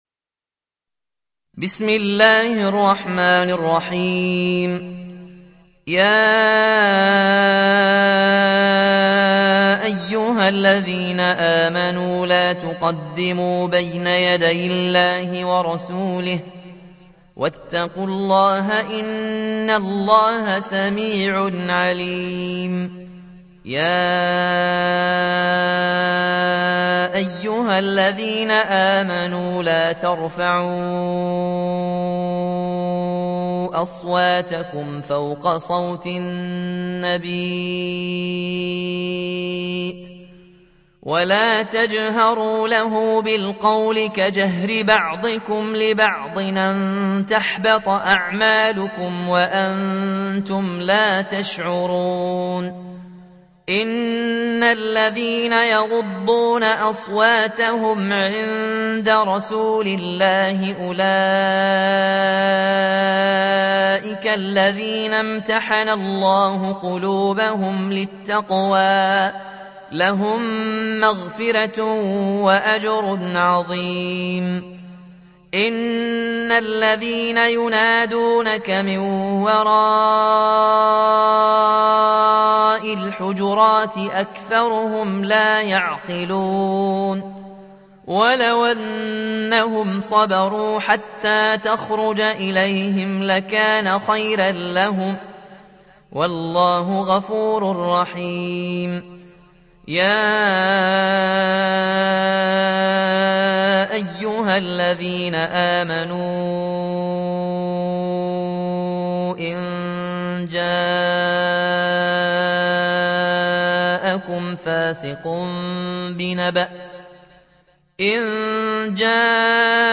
49. سورة الحجرات / القارئ